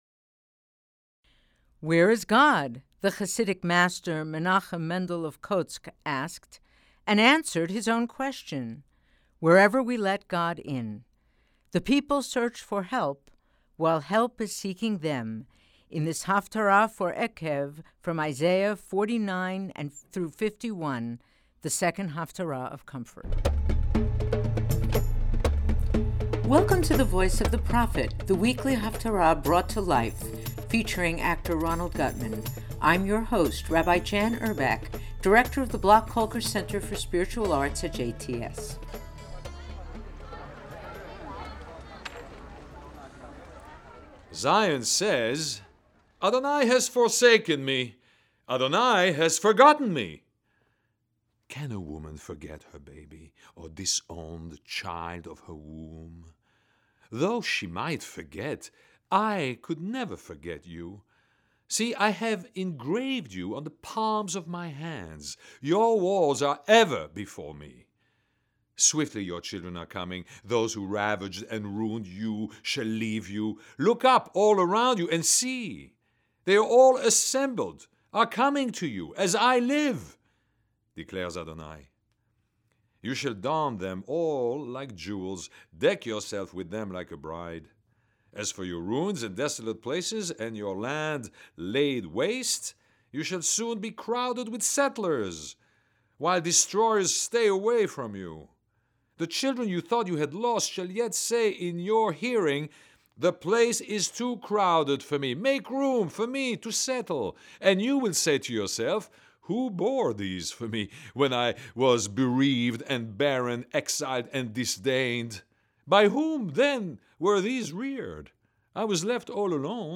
Eikev Posted On Jan 1, 1980 | Haftarah Reading